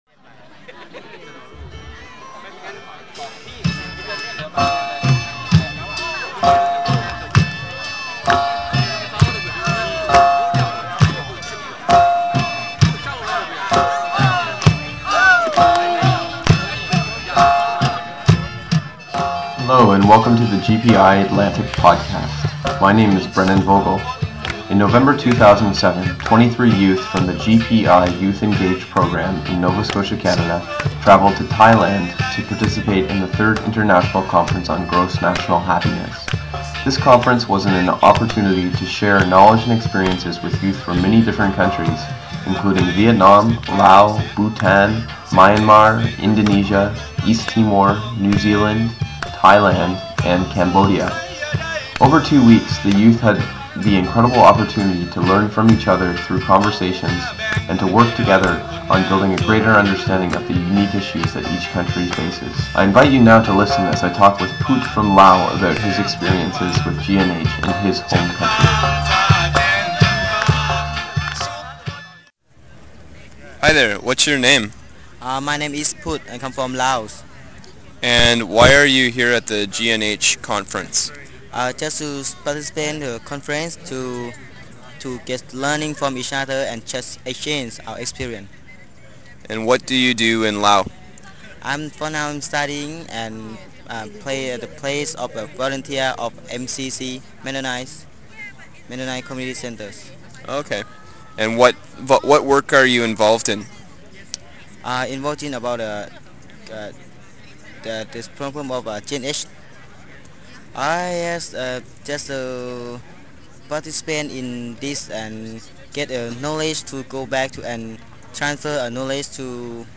As well, we have posted a podcast that contains numerous interviews with participants of the Third International Conference on Gross National Happiness.